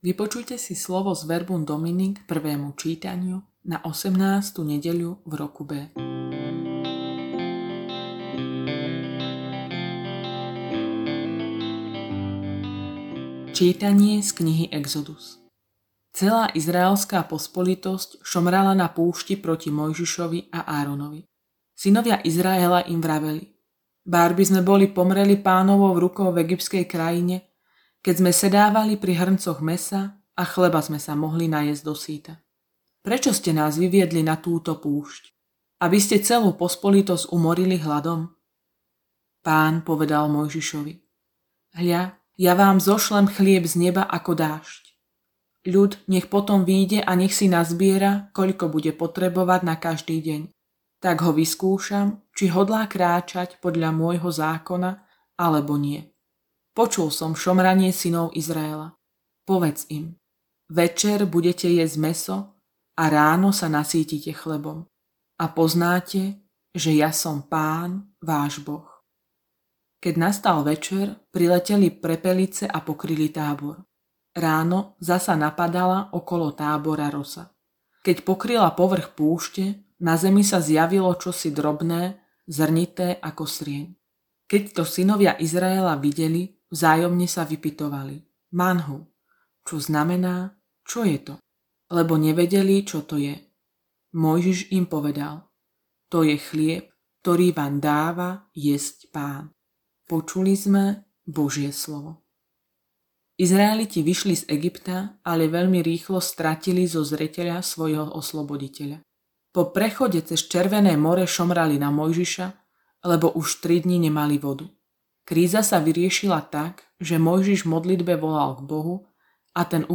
Audionahrávka zamyslenia…